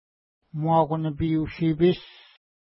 ID: 427 Longitude: -62.4381 Latitude: 54.0756 Pronunciation: mwa:ku-nipi:u-ʃi:pi:s Translation: Loon Lake River (small) Feature: river Explanation: Named in reference to lake Muaku-nipi (no 426) from which it flows.